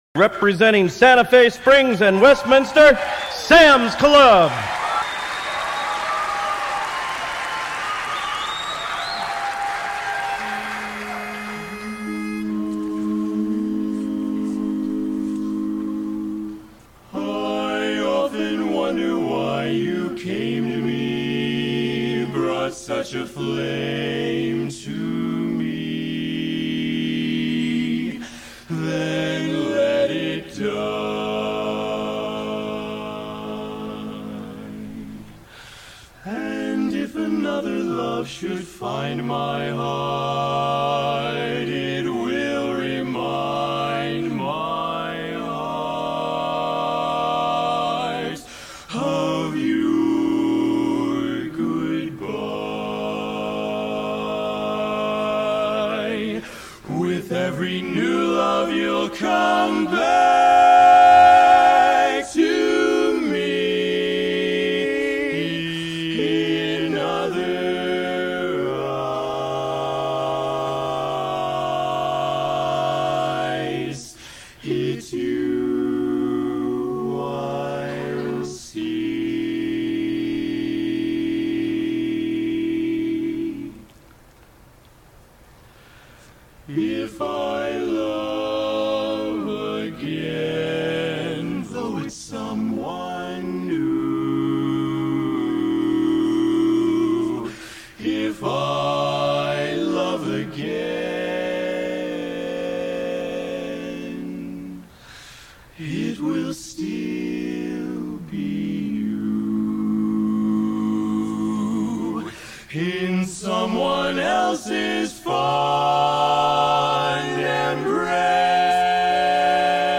The story behind the quartet...